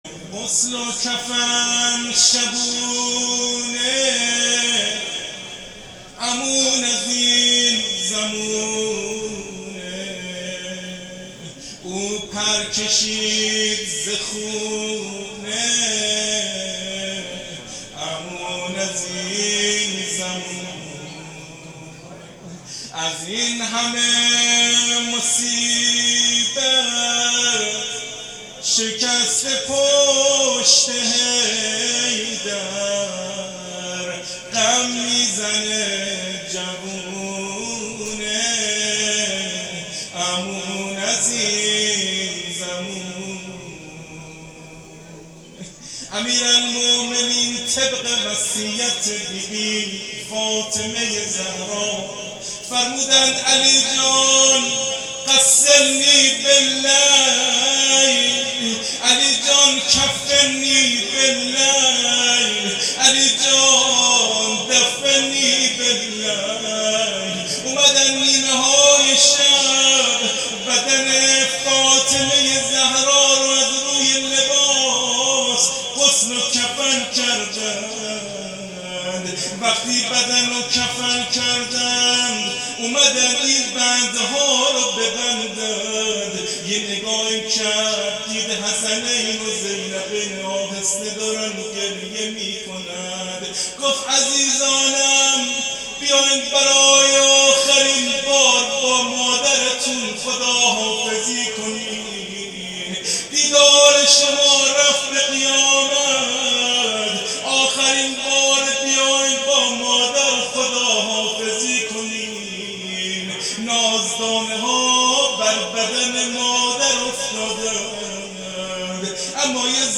روضه وداع فرزندان با مادر حضرت زهرا س